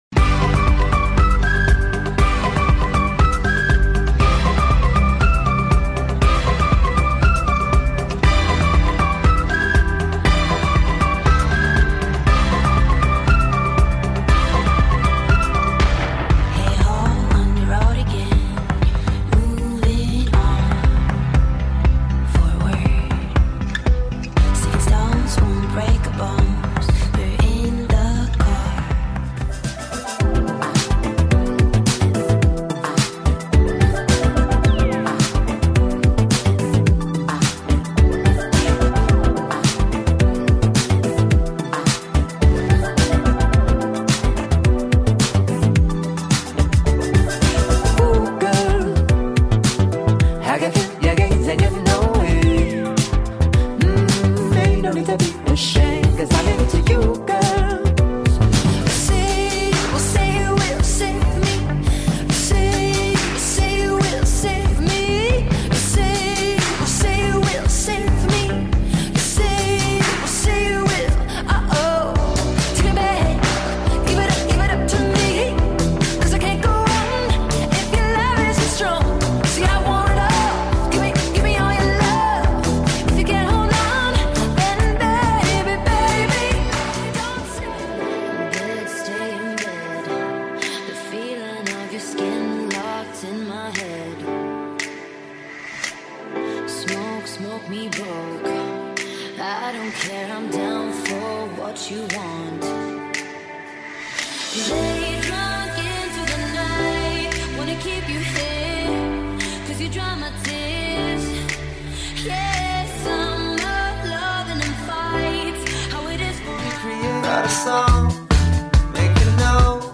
High Tempo Indie Dance
Best of Indie Dance Music